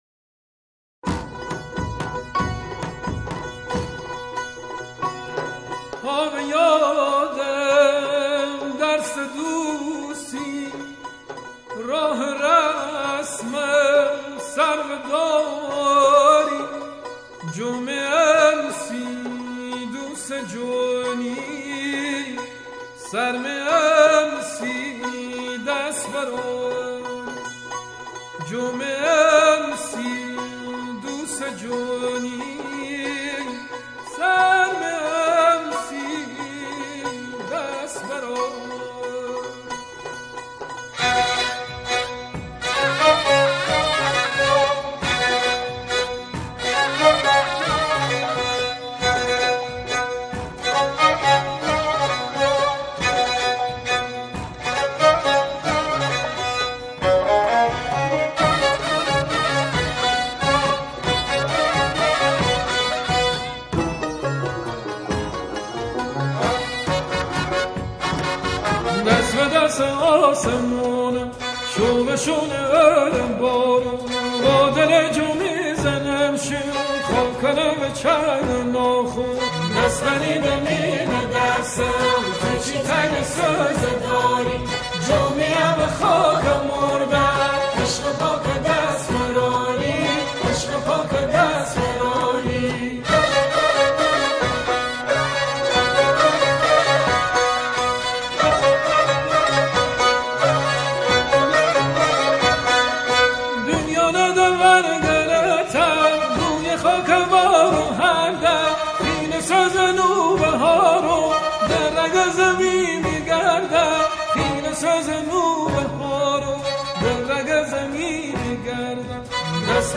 گروه کر